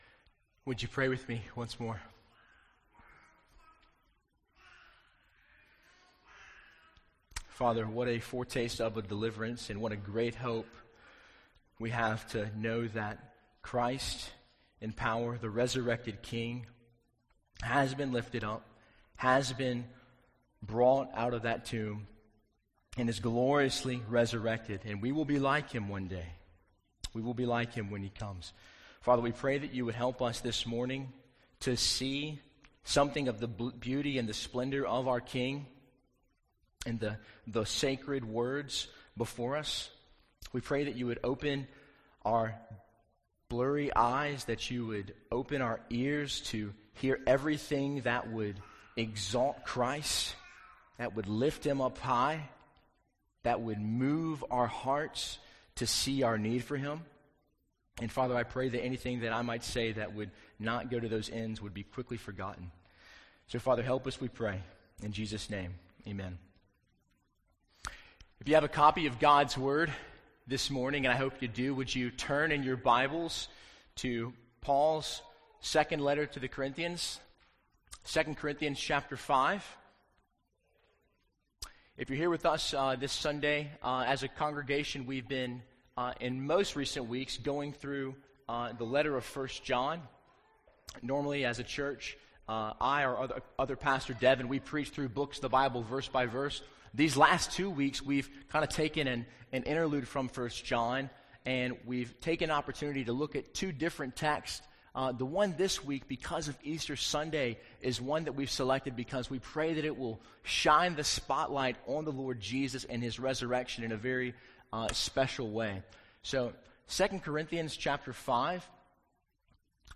Sermon Audio 2019 April 21